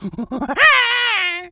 monkey_chatter_angry_03.wav